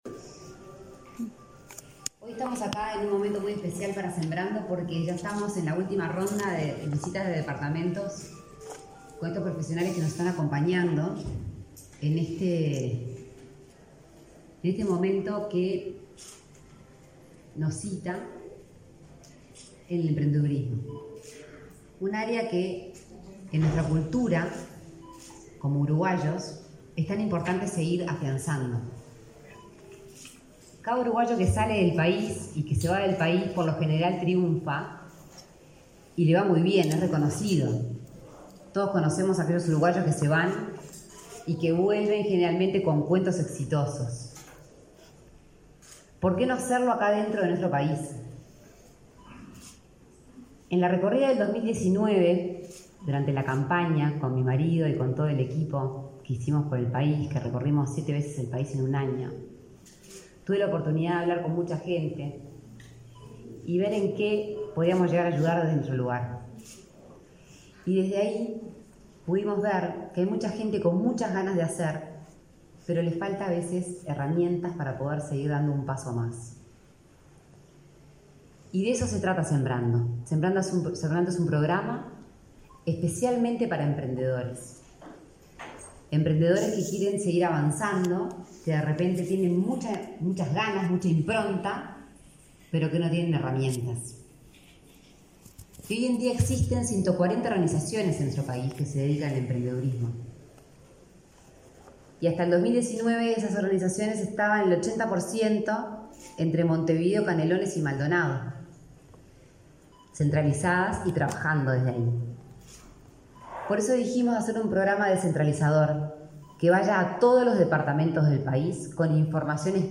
Conferencia de Lorena Ponce de León 26/11/2021 Compartir Facebook X Copiar enlace WhatsApp LinkedIn Este viernes 26, se realizó en Maldonado un seminario de Sembrando dirigido a emprendedores o personas con ideas para concretar negocios. En ese contexto, la impulsora del programa, Lorena Ponce de León, brindó una conferencia de prensa.